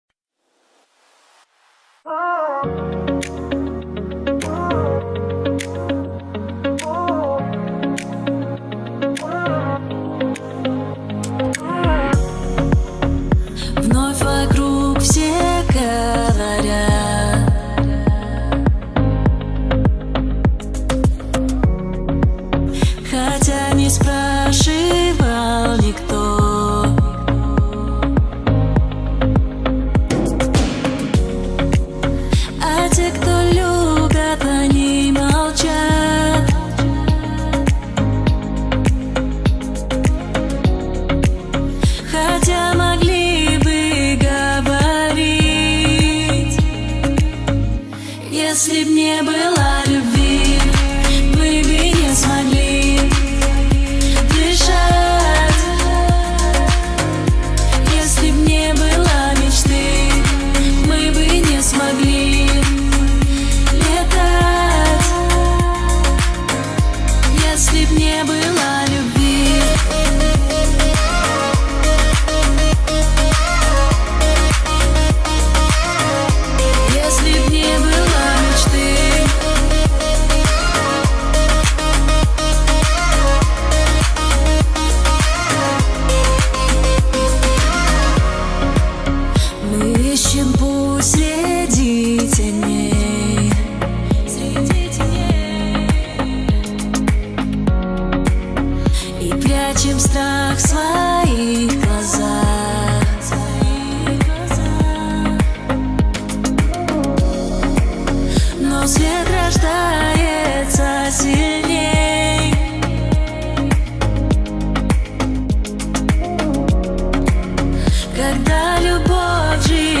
песня
345 просмотров 238 прослушиваний 28 скачиваний BPM: 101